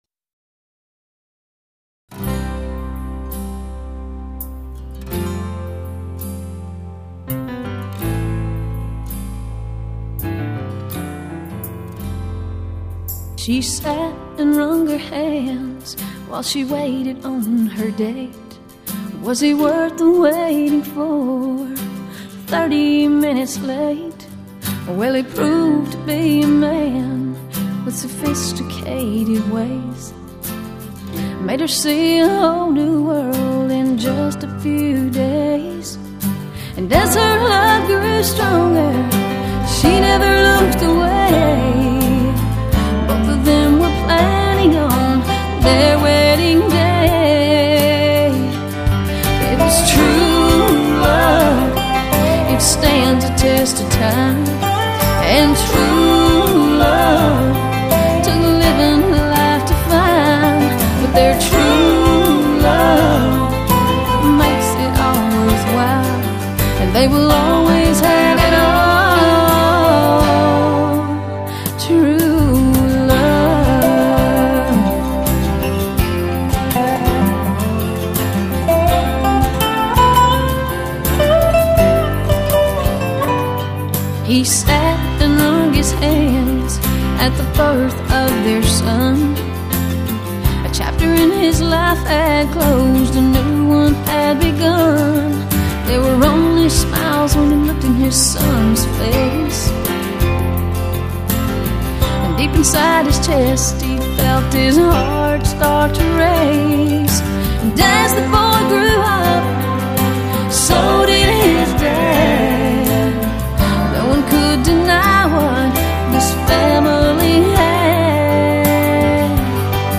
Nashville Recording Artist